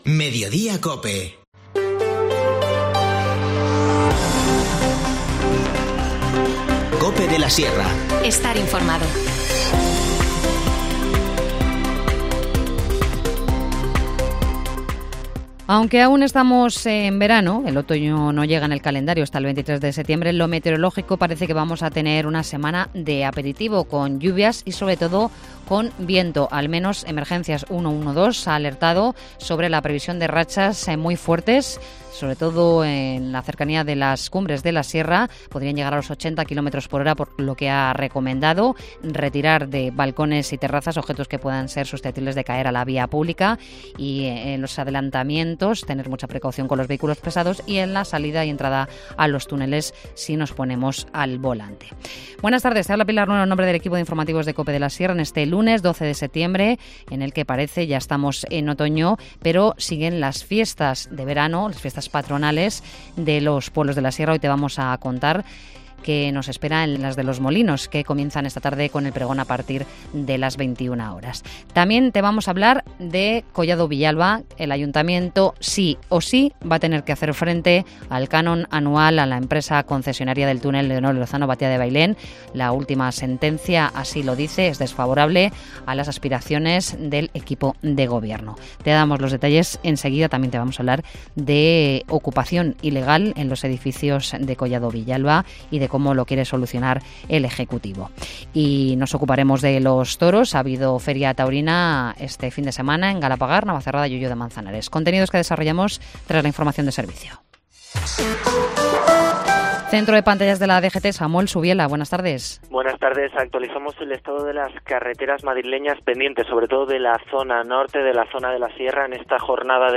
Informativo Mediodía 12 septiembre